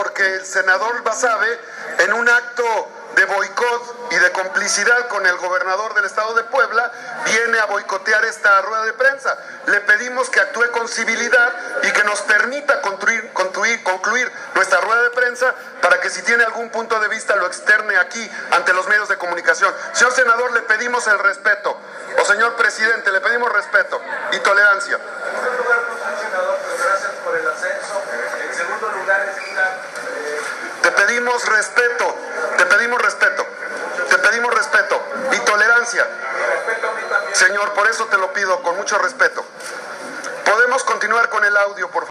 Fuera de sí, Armenta, quien durante las últimas ocho semanas se ha caracterizado por sus exabruptos en público y sus arranques de cólera a nombre de su candidata Blanca Alcalá, gritó a Basave -a quien además ubicó erróneamente como "senador"- y lo acusó de intentar "boicotear" una rueda de prensa en la que reiteró sus conocidas denuncias en contra del gobierno del estado de Puebla.
Escucha aquí el embate de Armenta contra Basave